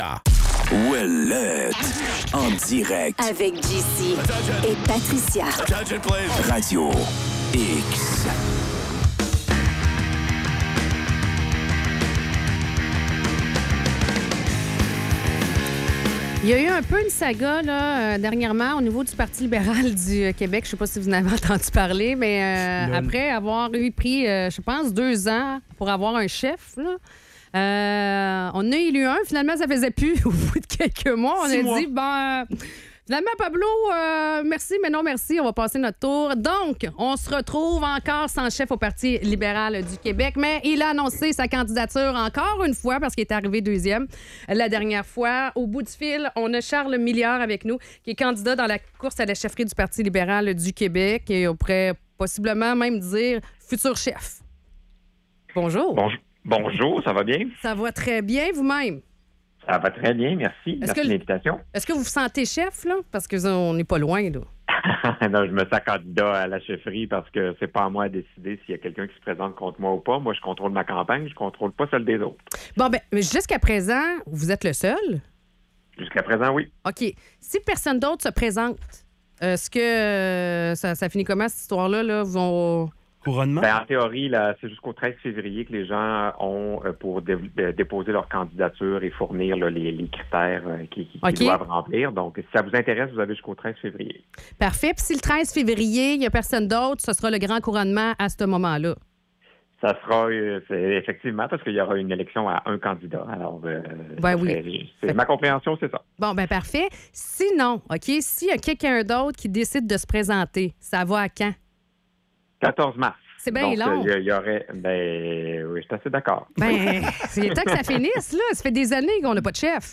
Entrevue avec le candidat à la chefferie du PLQ